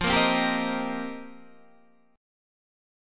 F9sus4 chord